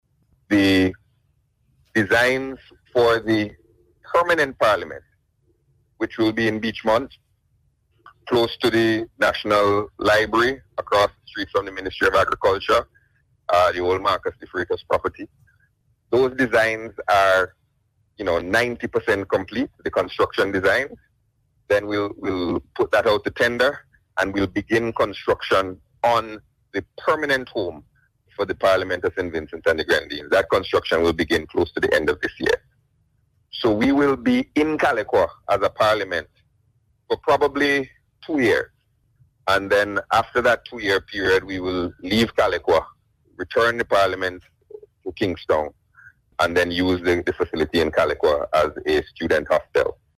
Minister Gonsalves, who is also the Parliamentary Representative for the area, provided an update on Radio on Sunday.